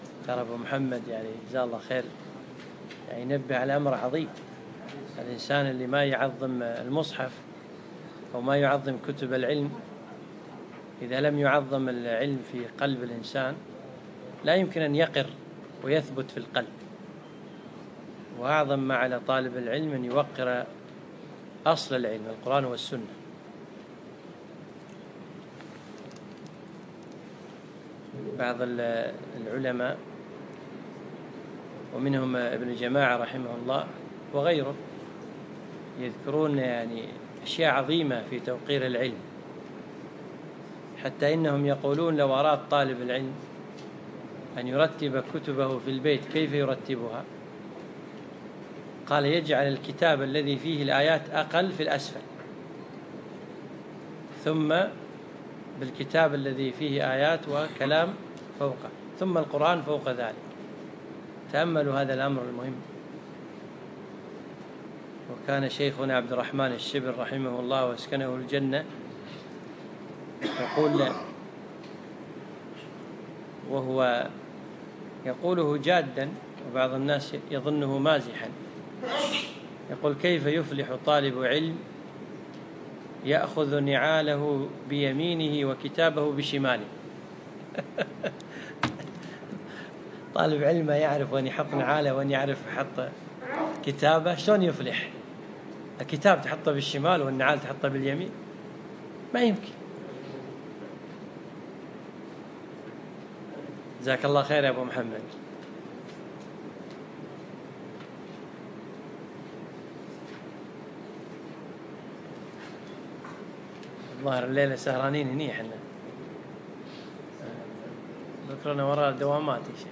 السبت 2 رجب 1437 الموافق 9 4 2016 مسجد سالم العلي الفحيحيل